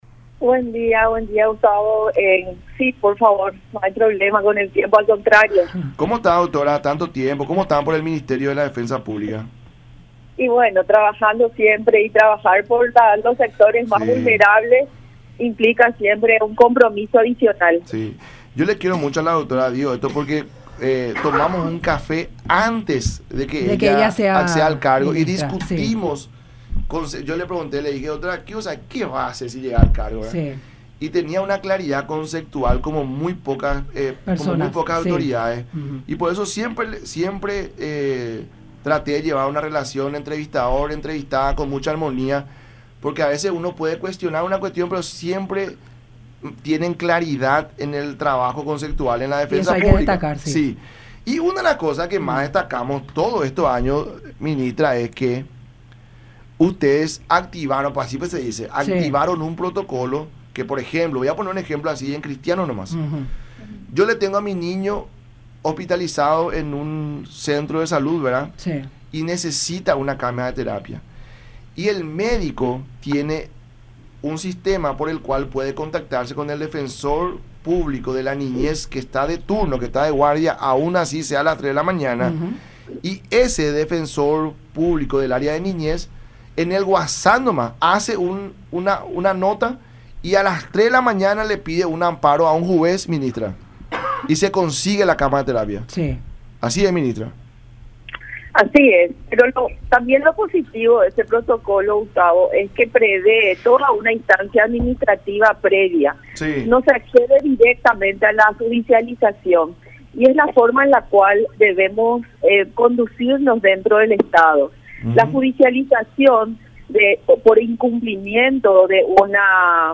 “Entiendo la preocupación del ministro con relación a la gestión de los recursos, pero por encima de esa realidad hay ciertas premisas sobre las cuales no podemos dejar de movernos, el derecho a la salud debe ser garantizado por el estado y debe proveer a la atención médica hasta lo máximo de sus recursos disponibles”, dijo Lorena Segovia en charla con “La Mañana de Unión” por Unión TV y radio La Unión.